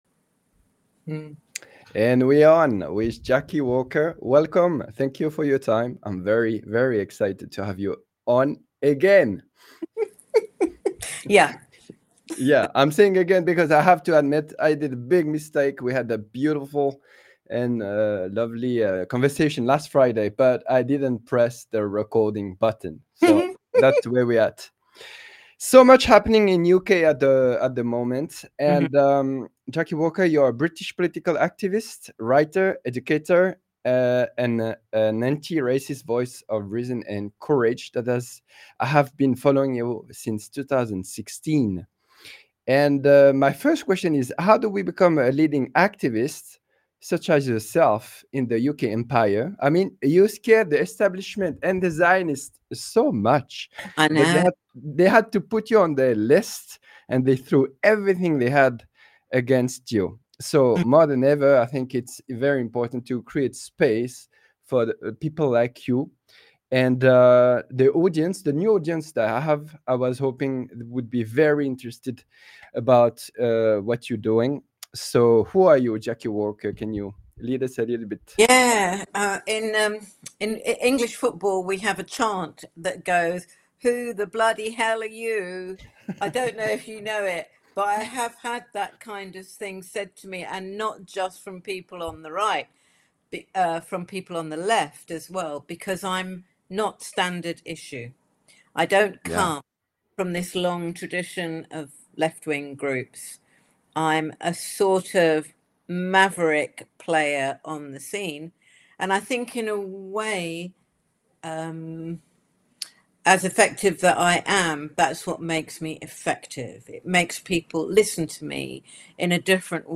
TheSwissBox Conversation